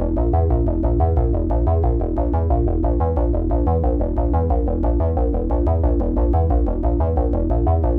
Index of /musicradar/dystopian-drone-samples/Droney Arps/90bpm
DD_DroneyArp4_90-E.wav